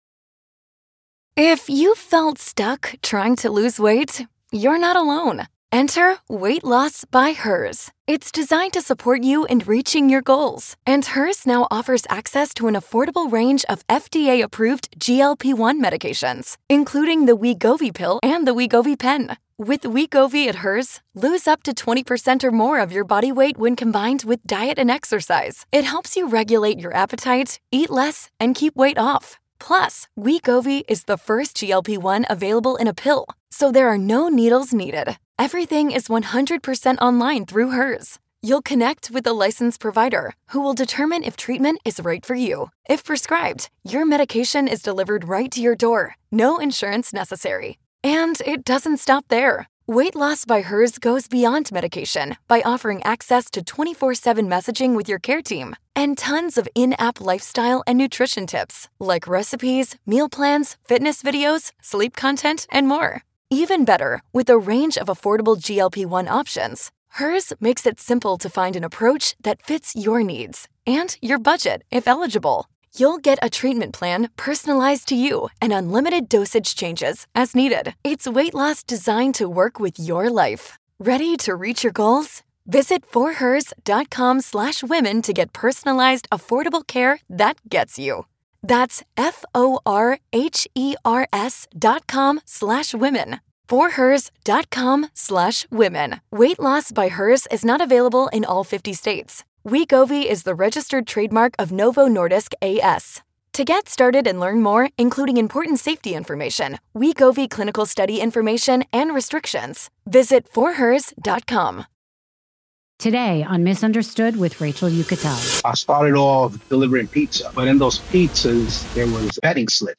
This conversation is about identity, accountability, and the courage it takes to stop surviving and start choosing something more.